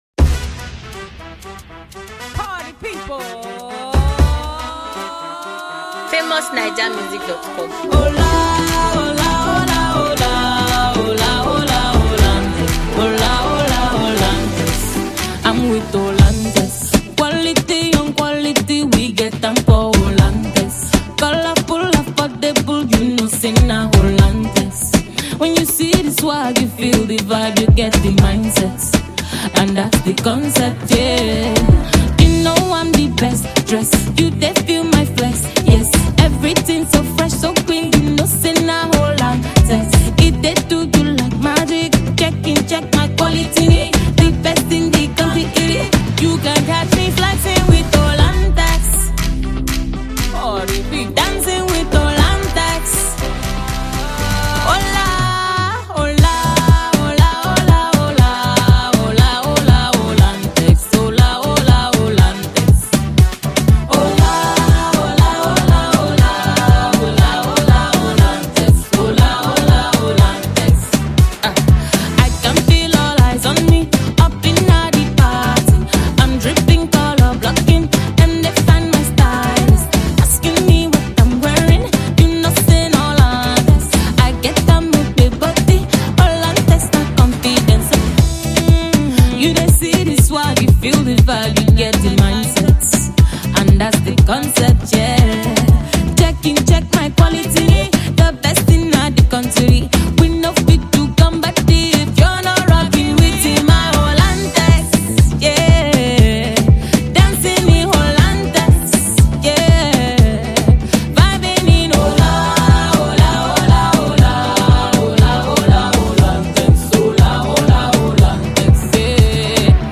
sharp sweet new tune
publicizing Jingle